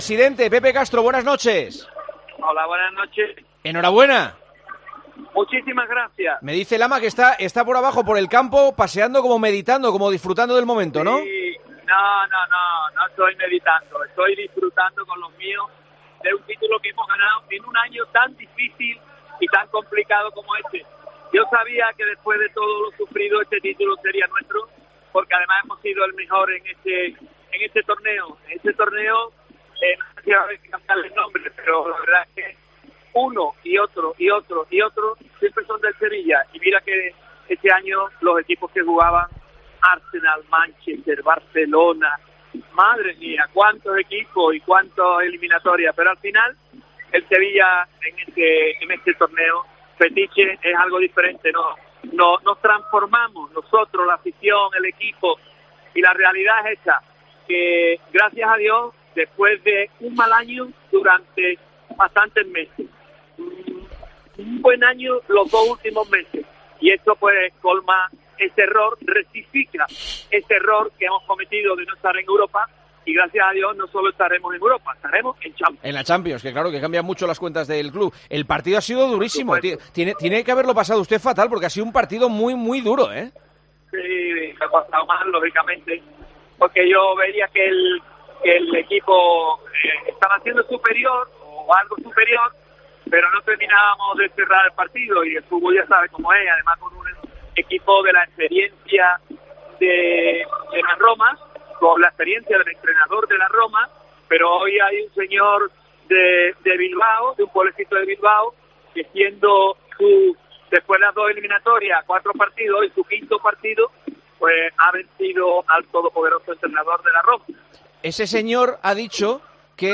El presidente del Sevilla se pasó por los micrófonos de El Partidazo de COPE tras conquistar su séptima Europa League ante la Roma.